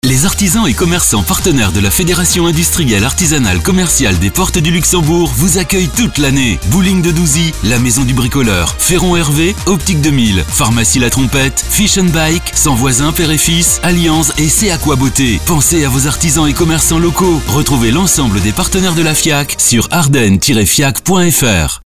Spot publicitaires des Artisans Commerçants des Portes du Luxembourg (Ardennes